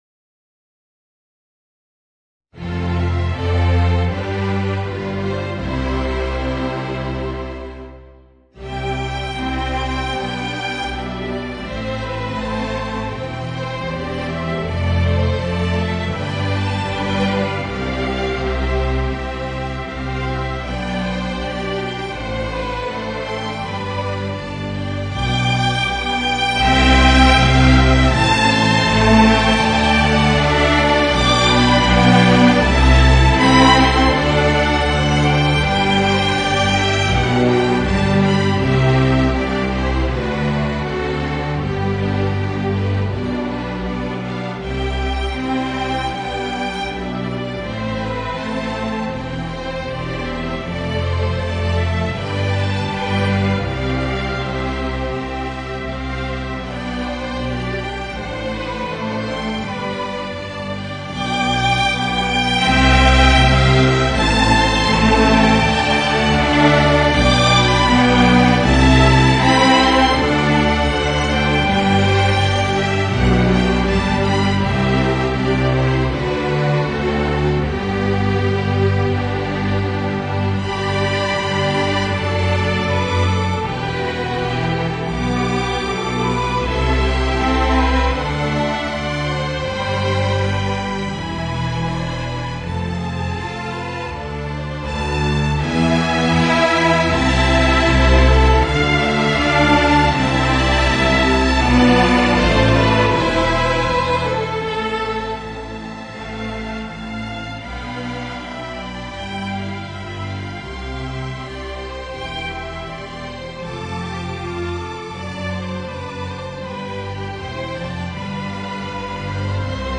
Voicing: Viola and String Orchestra